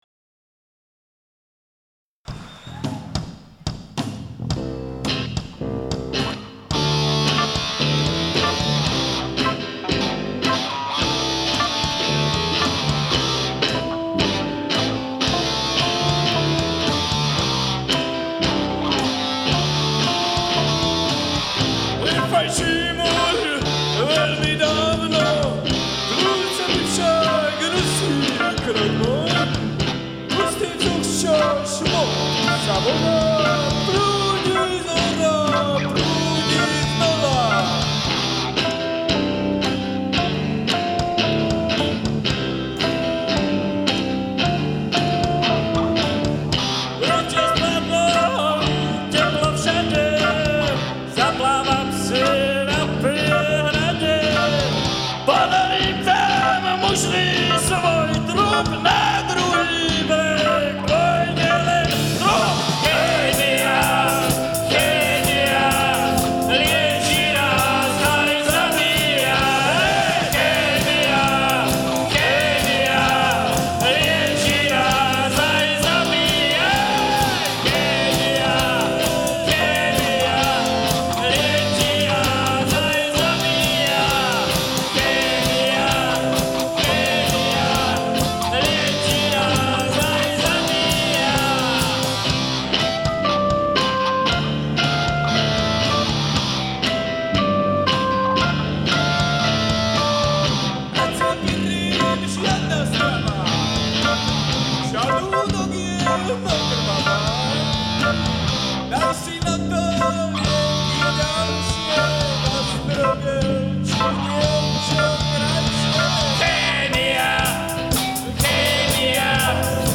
Nahráte: Live Kino Hviezda Trenčín 16.12.2000